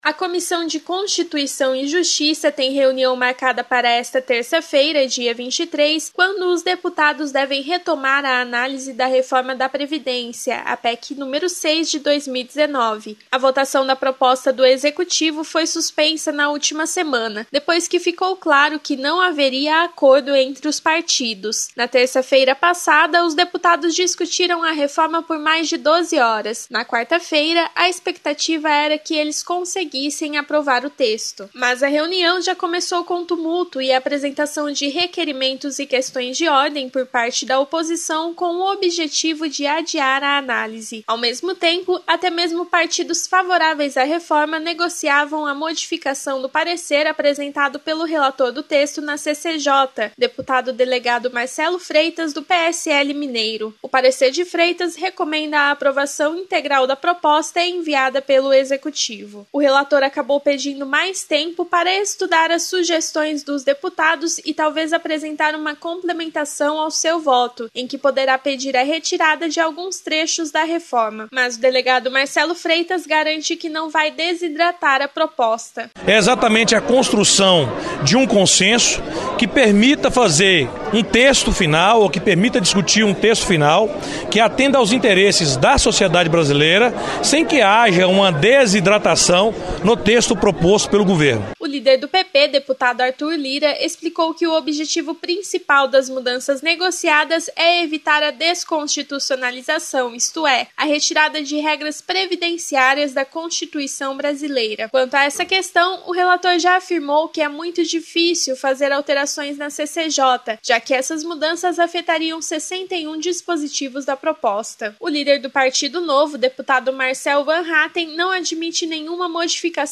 Outras notícias na programação da Rádio Cultura AM 930